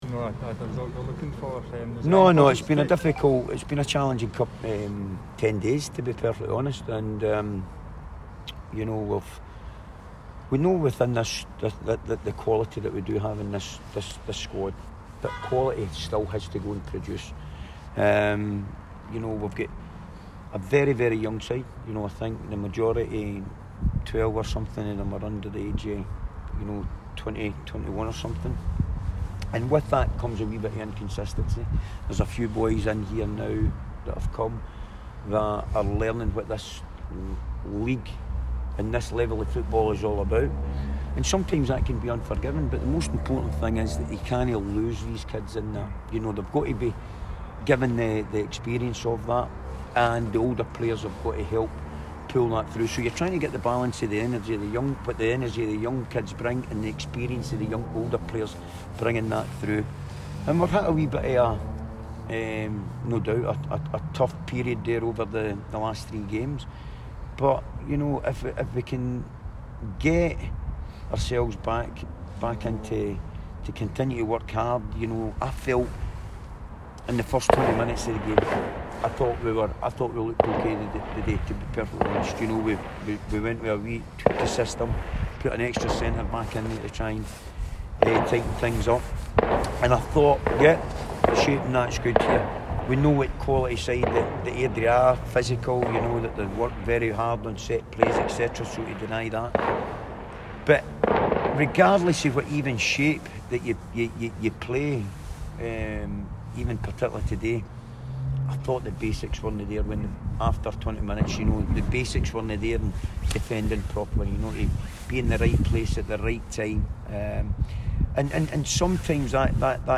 press conference after the League 1 match.